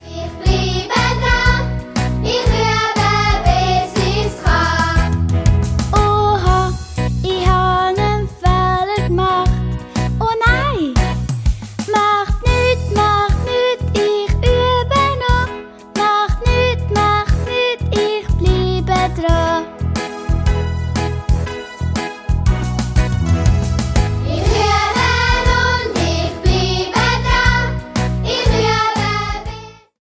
Musikalisches Hörspiel zum Schulstart